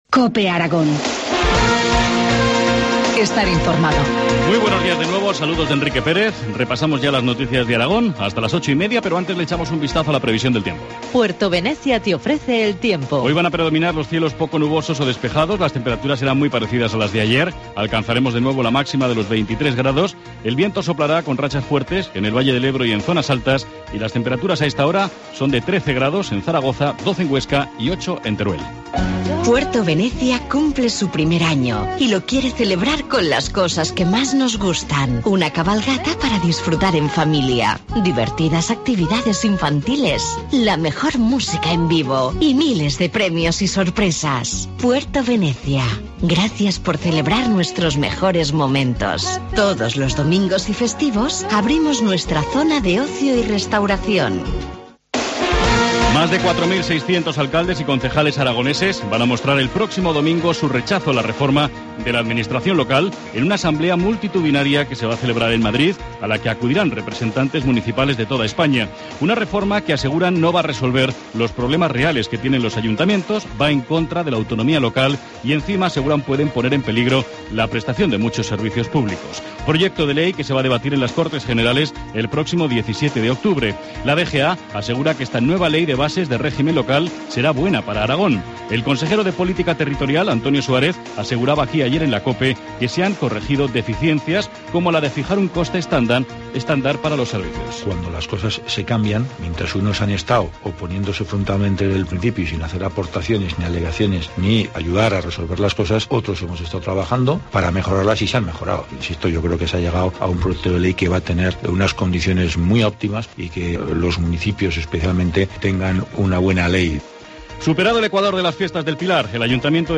Informativo matinal, jueves 10 de octubre, 8.25 horas